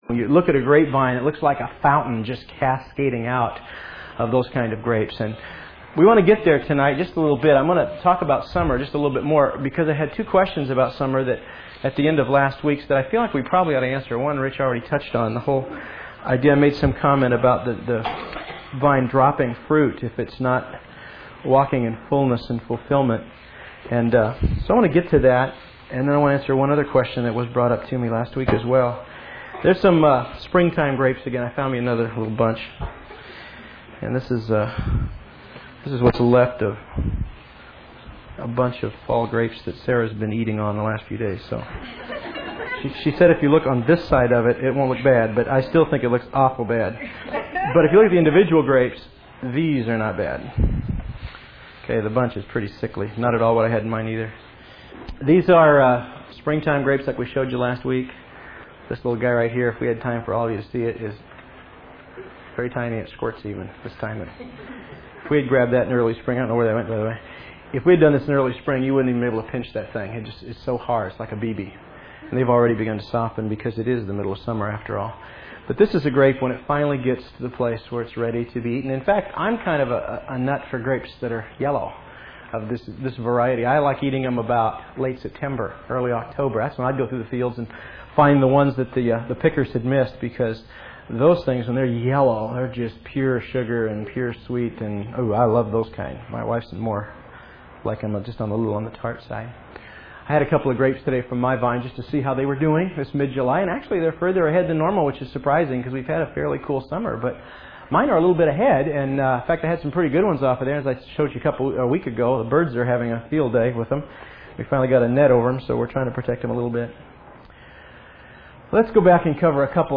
This has not been available for awhile because of tape problems, but we were able to resurrect it using digital technology.